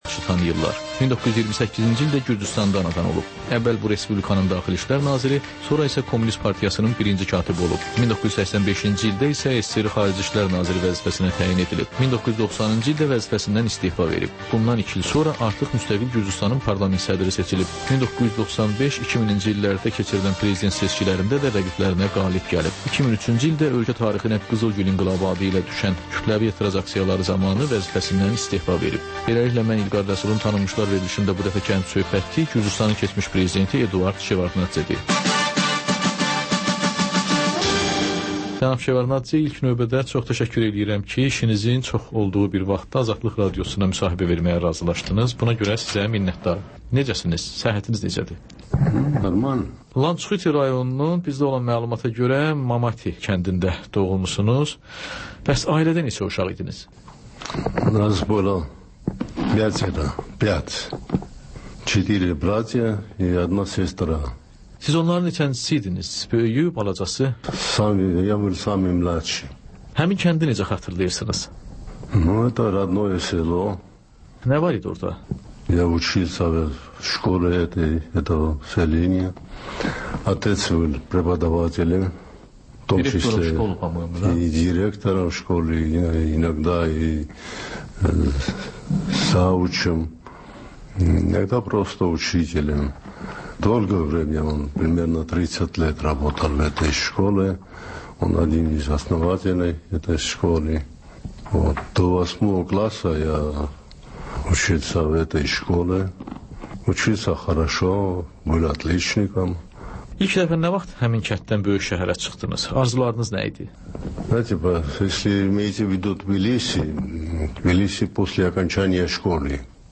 Ölkənin tanınmış simalarıyla söhbət.